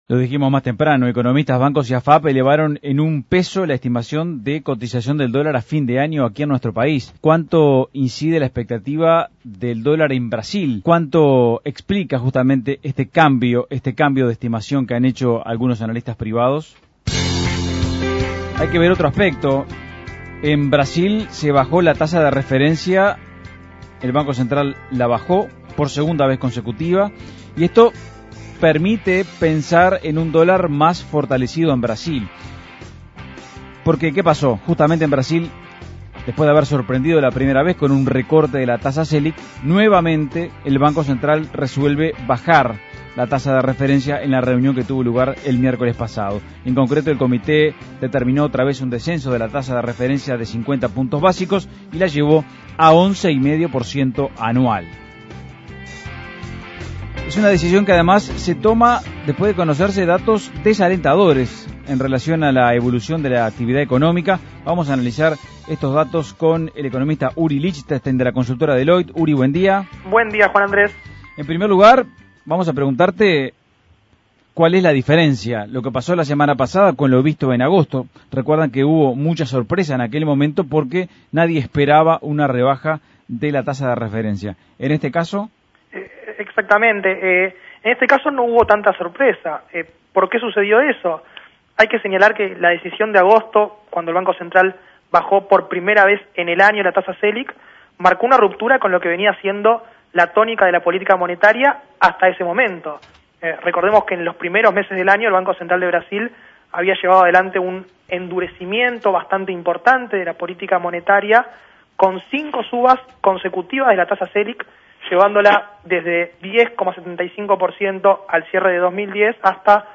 Análisis Económico El Banco Central de Brasil recortó la tasa de política monetaria por segunda vez consecutiva.